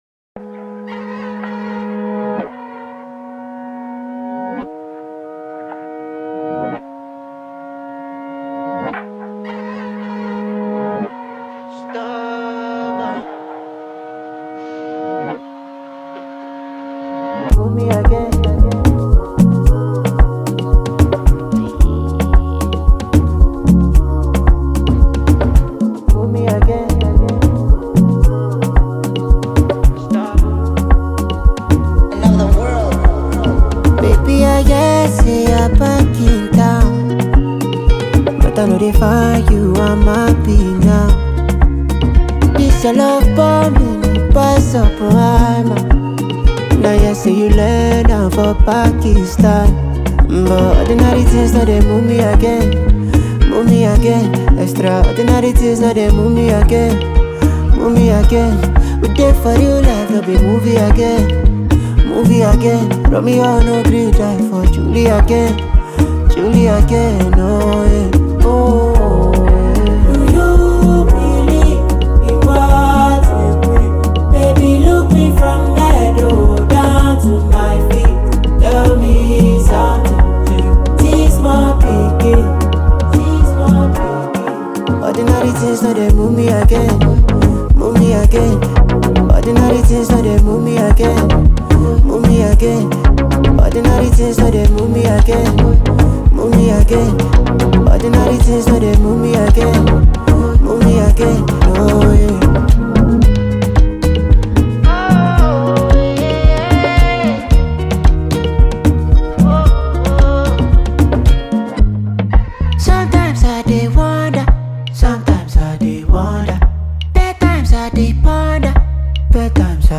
From smooth melodies to hard-hitting rhythms